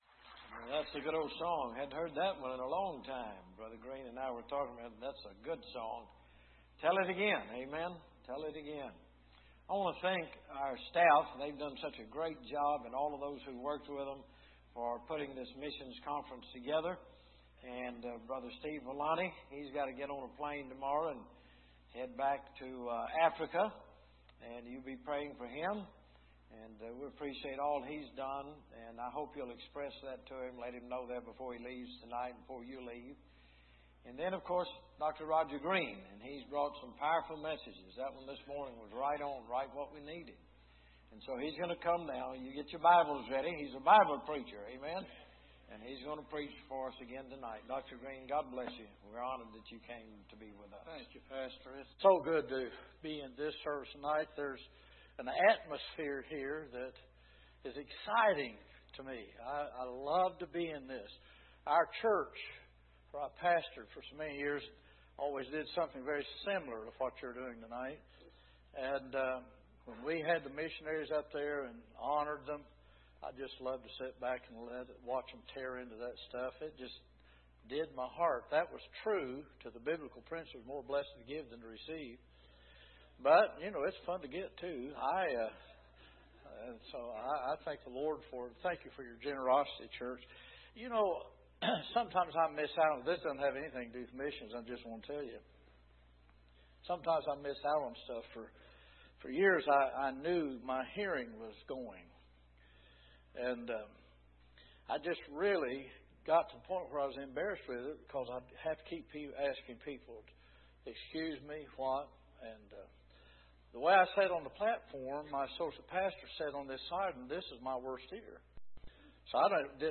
Missions Conference 2015
Sermons